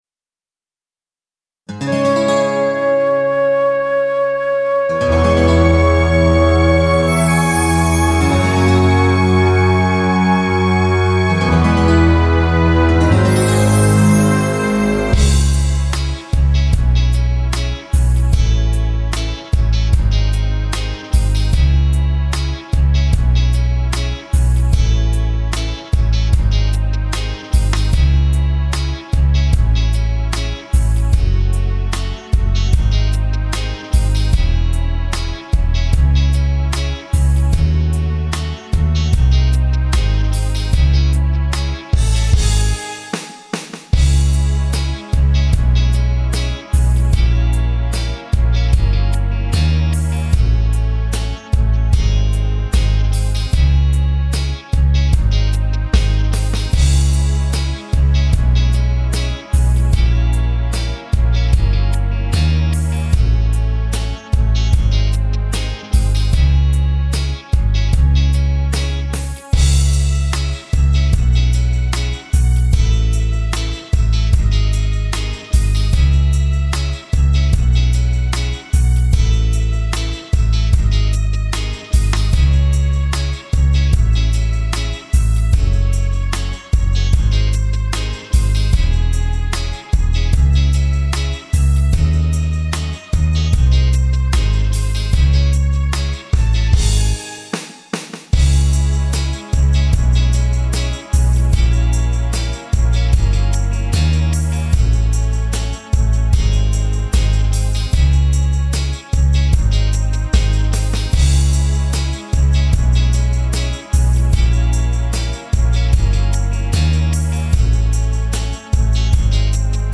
• Качество: Хорошее
• Категория: Детские песни
караоке